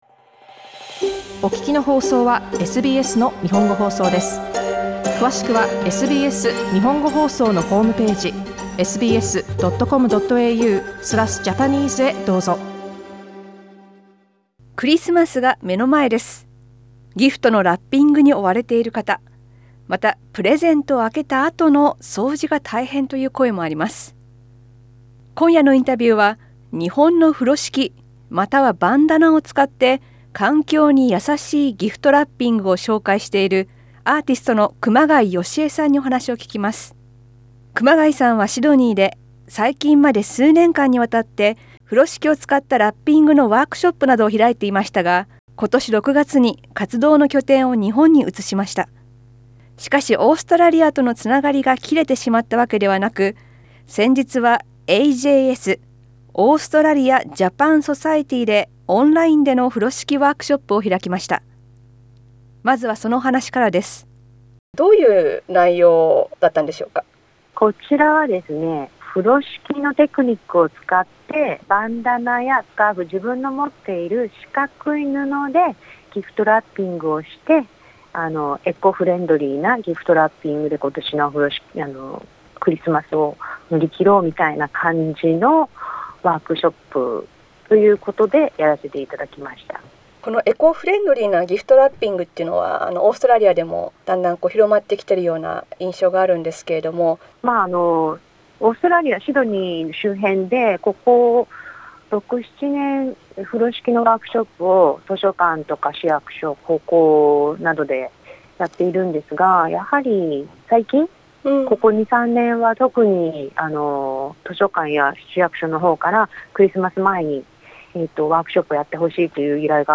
インタビューでは、そのオンラインワークショップの話や、風呂敷に関心を持ったきっかけなどを聞きました。